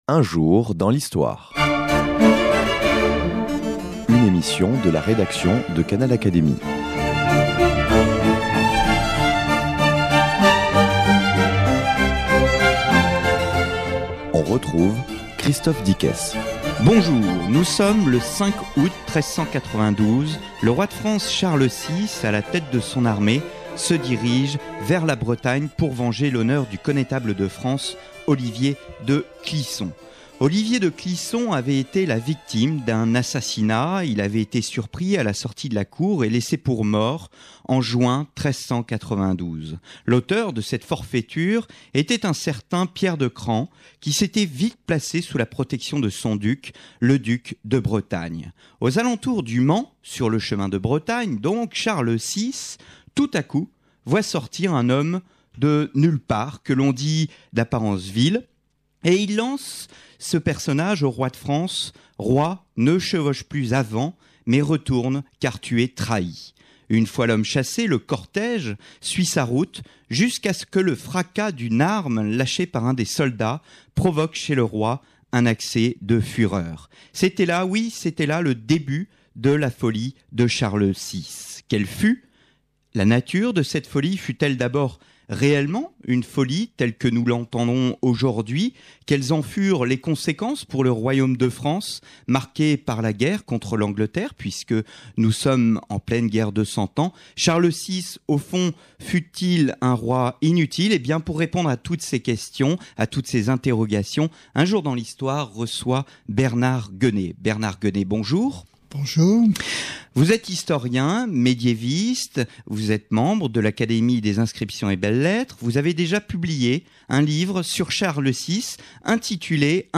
Un souverain fragile pourtant fort aimé de son peuple. L’historien Bernard Guenée, membre de l’Académie des inscriptions et belles-lettres, est ici l’invité de Canal Académie pour présenter son ouvrage consacré au roi Charles VI dont les crises de folie ont assombri le règne et mis le royaume en péril.